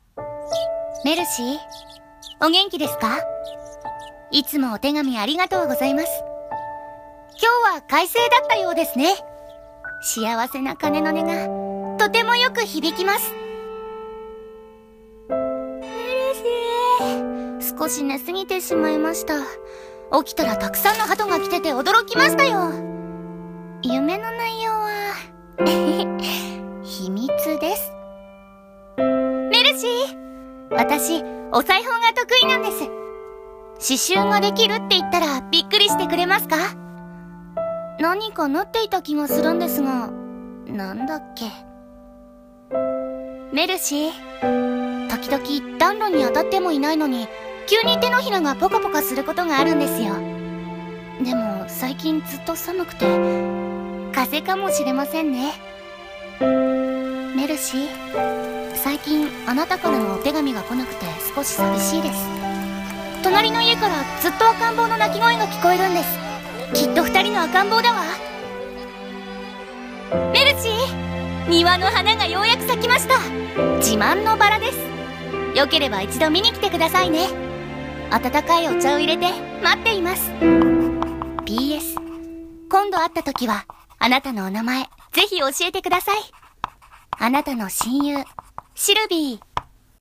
CM風声劇｢.letter｣